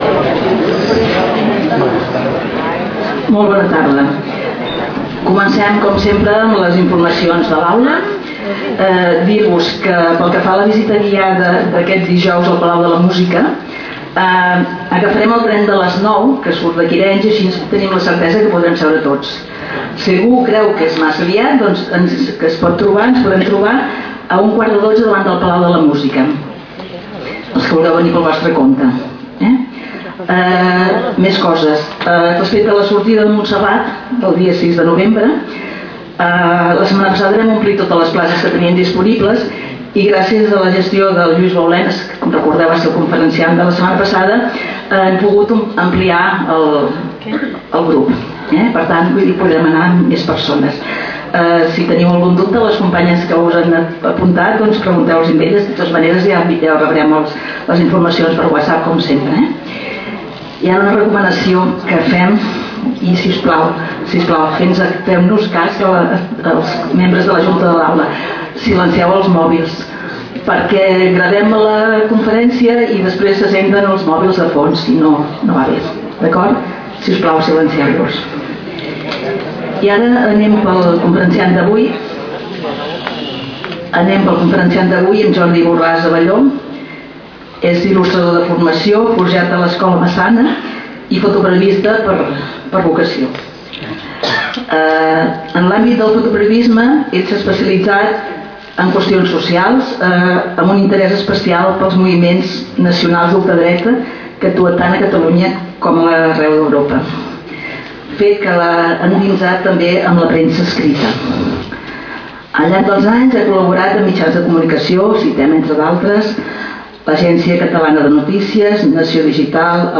Lloc: Casal de Joventut Seràfica
Categoria: Conferències